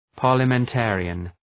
Shkrimi fonetik{,pɑ:rləmen’teərıən}
parliamentarian.mp3